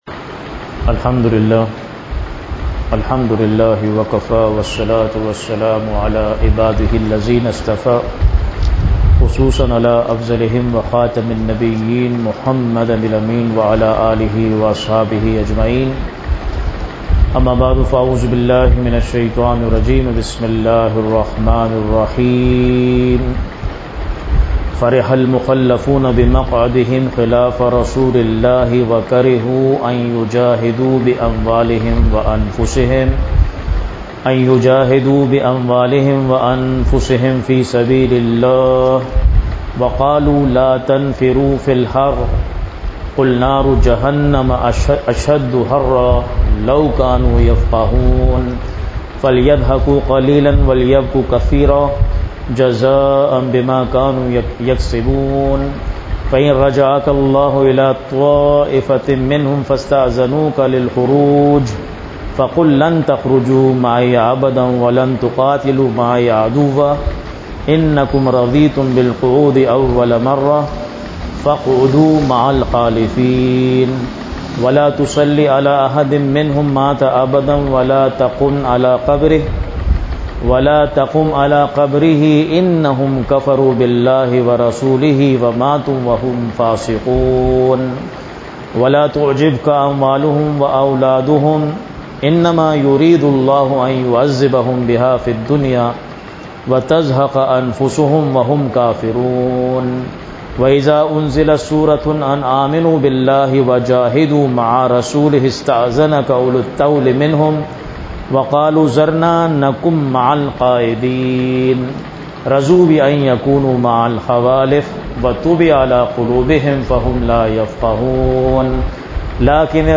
Weekly Dars-e-Quran